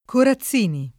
[ kora ZZ& ni ]